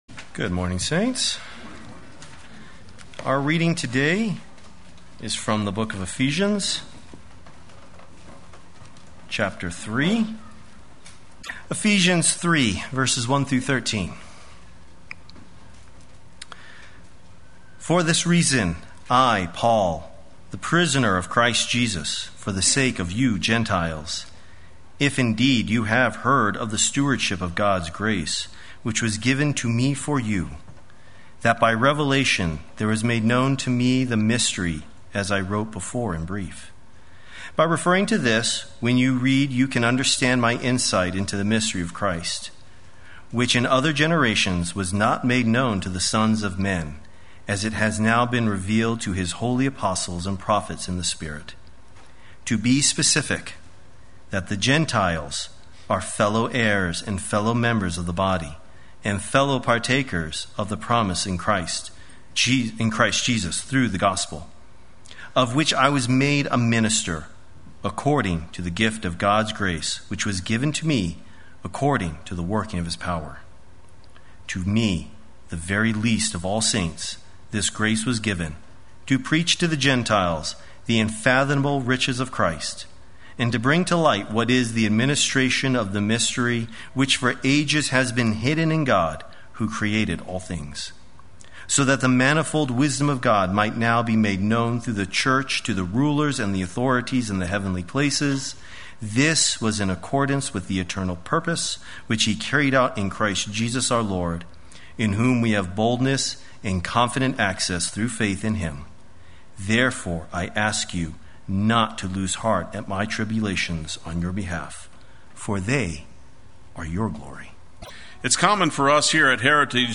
Play Sermon Get HCF Teaching Automatically.
“The Mystery of Christ” Sunday Worship